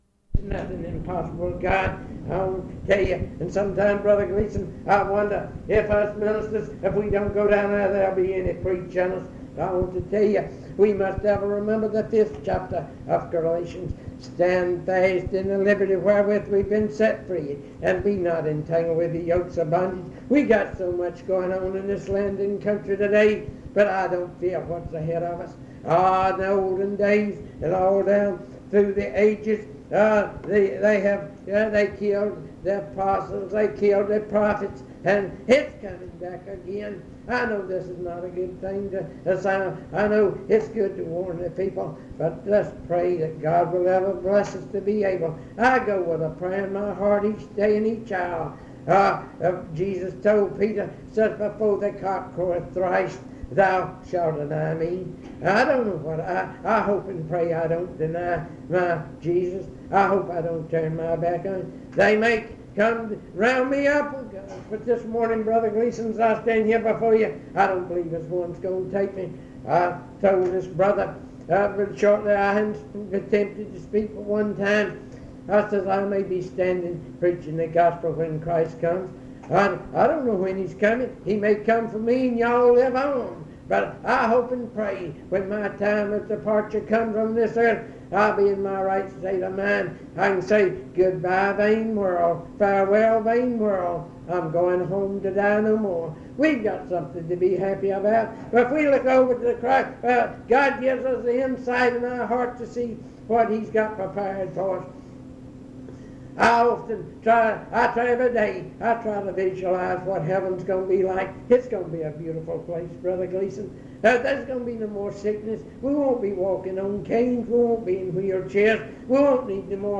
Location Guilford County (N.C.) Browns Summit (N.C.)